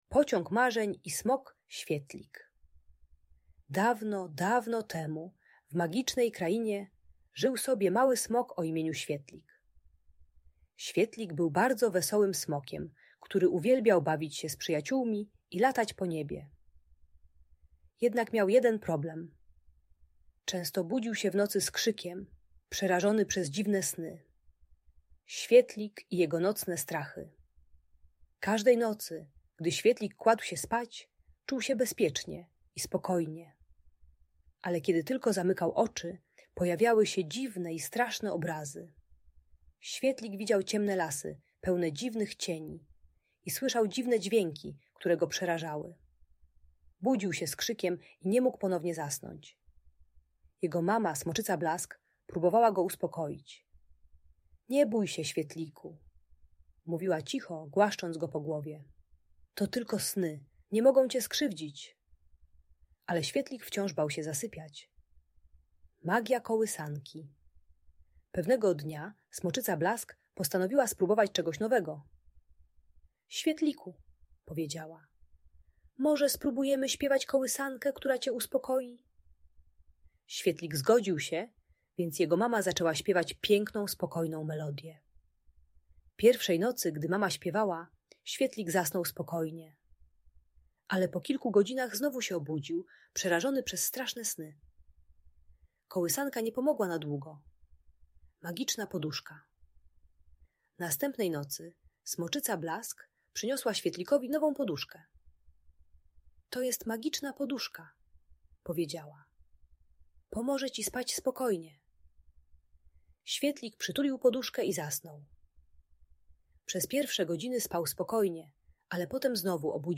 Pociąg Marzeń i Smok Świetlik - Bajka o Przezwyciężaniu Strachu - Audiobajka dla dzieci